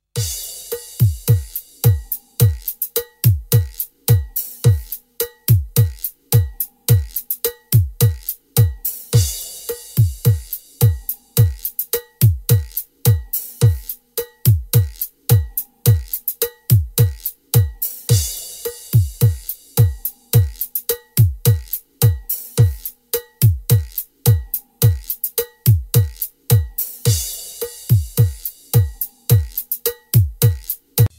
Thể loại: Nhạc nền video